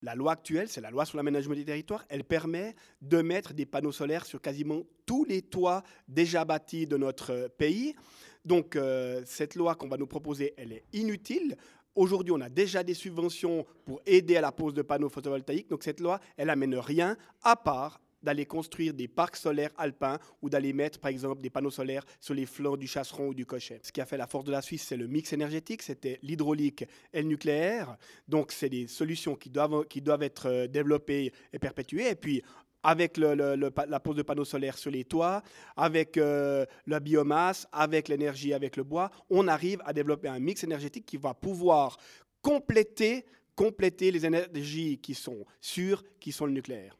Le conseiller national UDC Yvan Pahud, ainsi que le reste du comité contre la loi sur l'électricité, ne souhaitent pas laisser tomber l'éolien ou le solaire. Mais cela ne suffit pas selon le député :